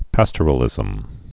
(păstər-ə-lĭzəm, pästər-)